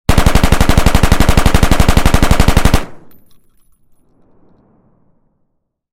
Хороший звук выстрелов автомата